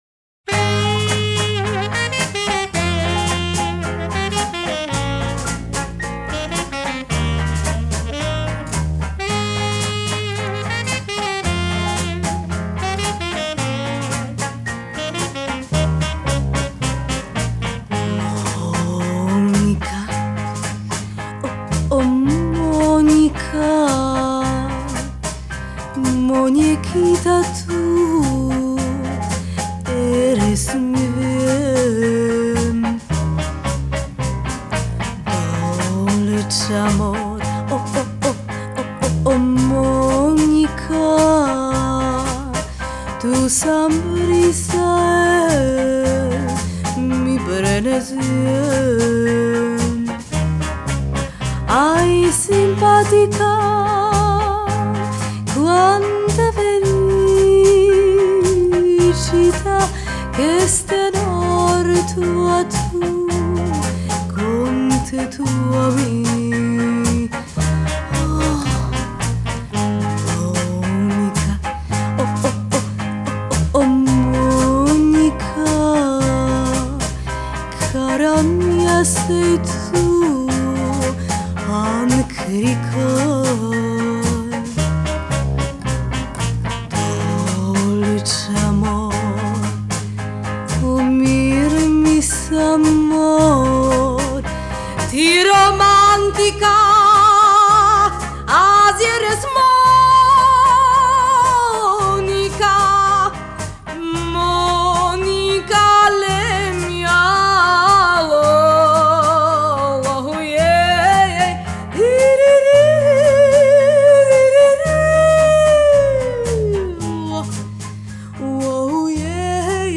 Genre: Retro, Twist, 60s, Female Vocal